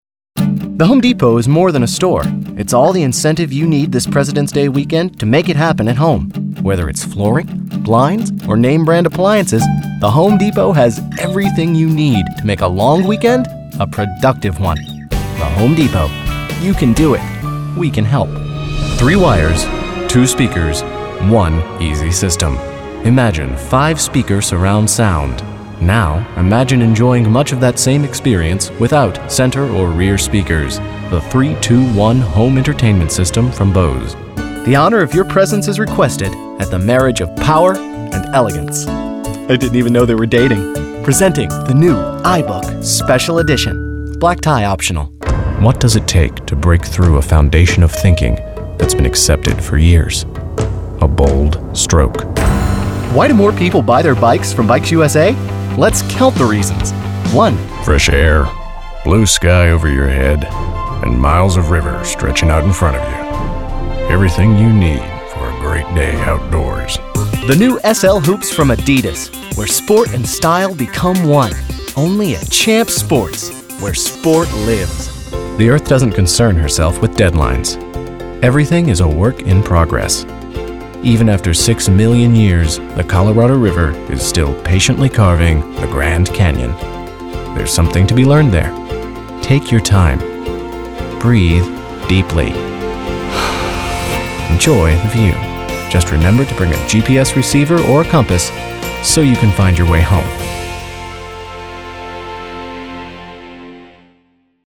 Voice Reel
Recorded at In Your Ear Studios, Richmond VA.